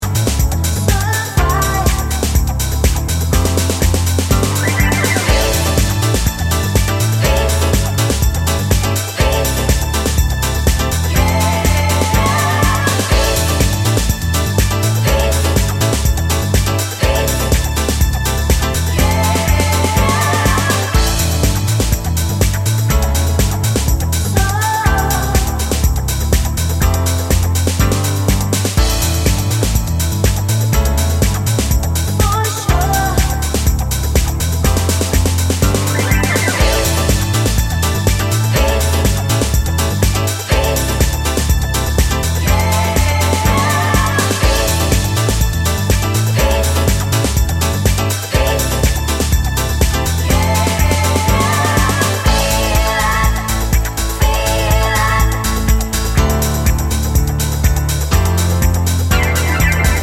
no Backing Vocals Dance 4:00 Buy £1.50